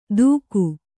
♪ dūku